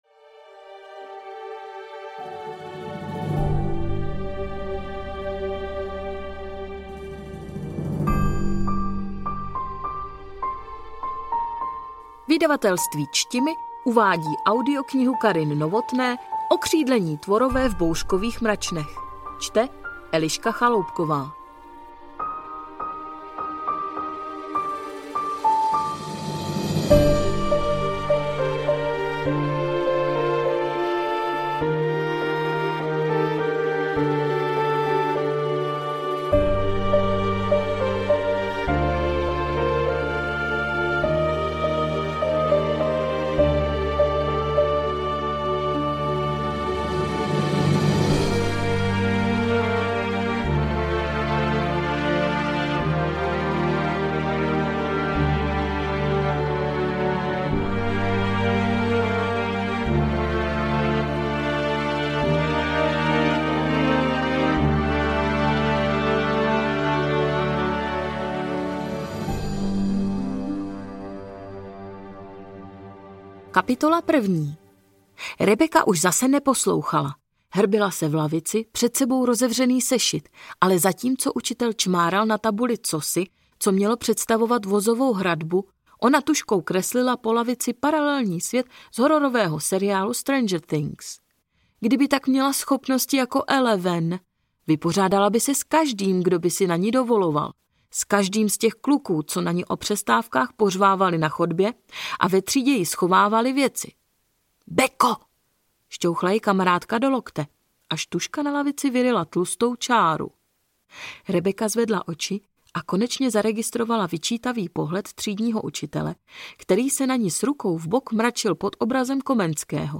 Ukázka z knihy
okridleni-tvorove-v-bourkovych-mracnech-audiokniha